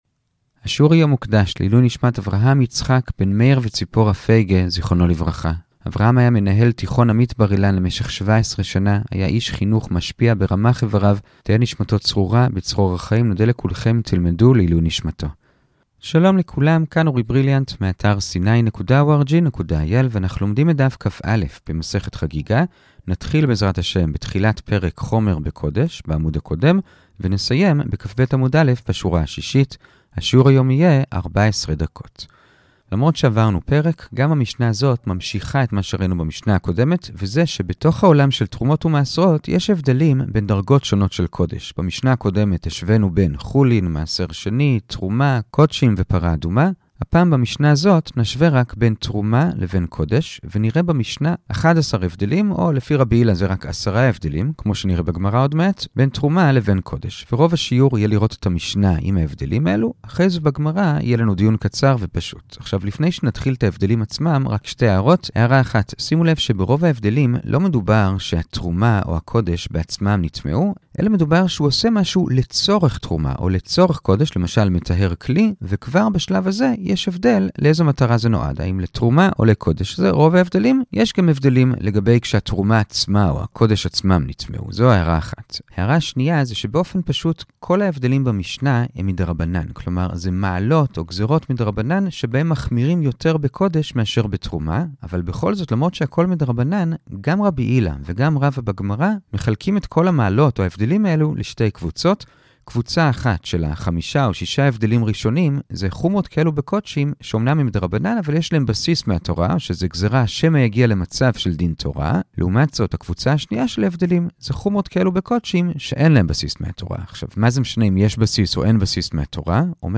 הדף היומי מסכת חגיגה דף כא - שיעור קצר וברור במיוחד ברבע שעה בלבד
השיעור המשולב (תרשים יחד עם קריאה בגמרא)